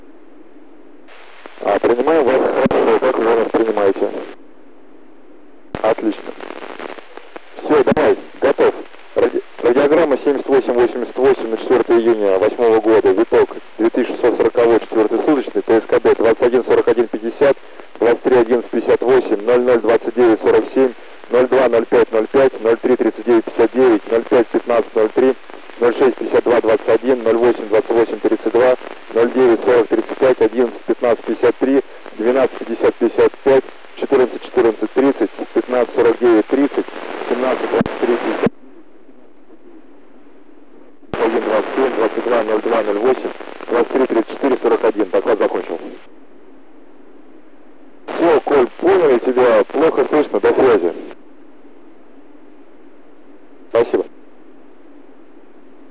Начало » Записи » Записи радиопереговоров - МКС, спутники, наземные станции
Запись радиообмена с МКС.
Проход над Хабаровском 4.06.08 в 00:15 местного.
Волков передает на Землю радиограмму.